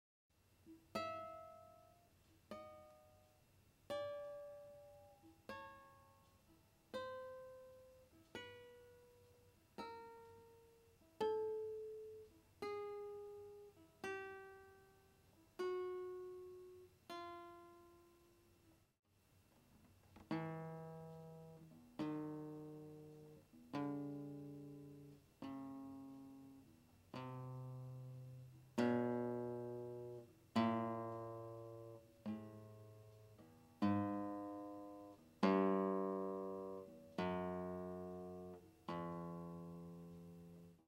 97. Bitones.m4v